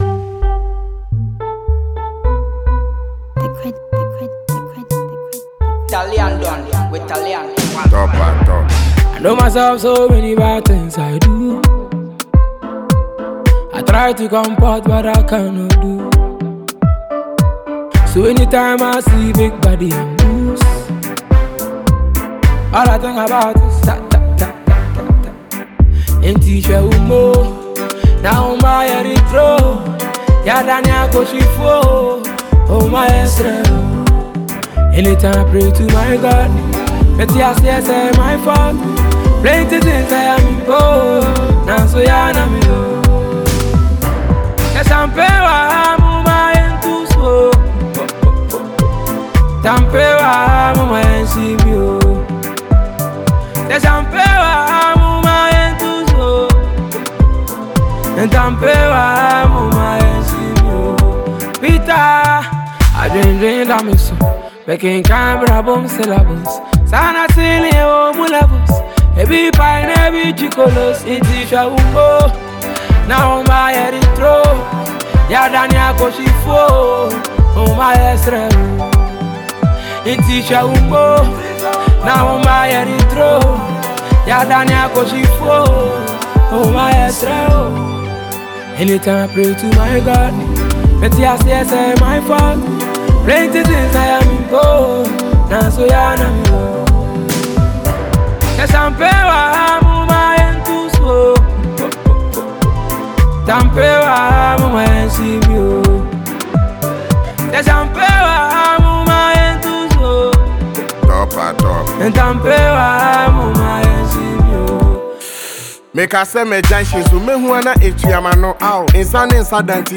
New banger from Ghanaian singer
rapper